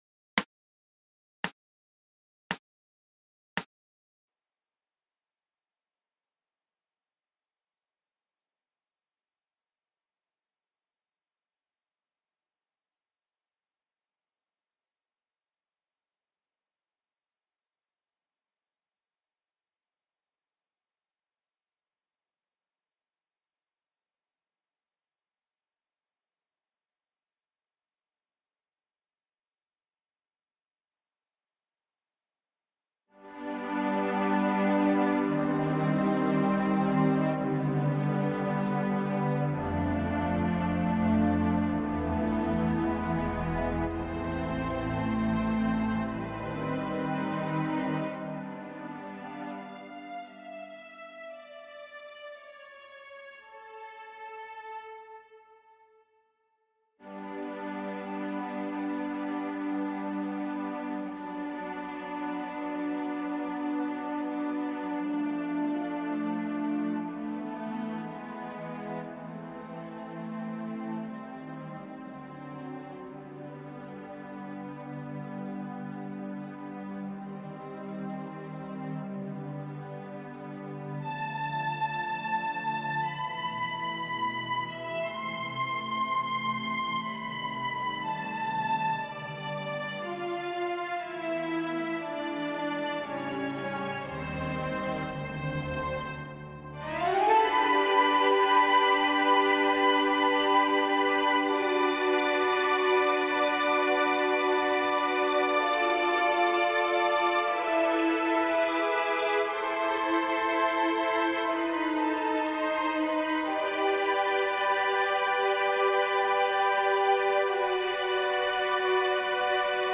VIOLINES (Descargar)